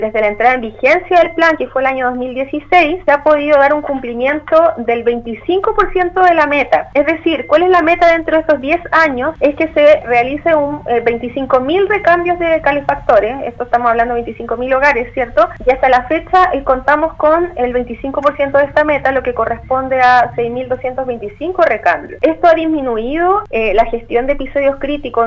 En conversación con Radio Sago, la Seremi de Medioambiente (s) de la región de Los Lagos, Bárbara Herrera, dio a conocer detalles del inicio de un nuevo período de Gestión de Episodios Críticos, GEC 2022, en el marco del desarrollo del séptimo año de vigencia del Plan de Descontaminación Atmosférica de la comuna.